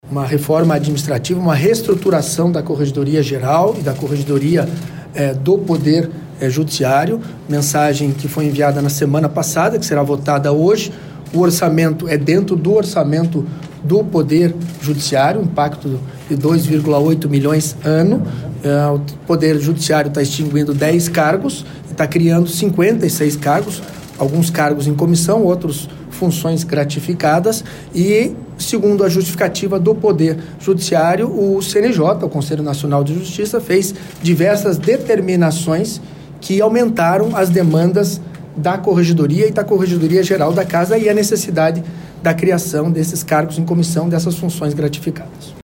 O presidente da Assembleia, deputado Alexandre Curi (PSD), disse que a ampliação representa uma reestruturação interna.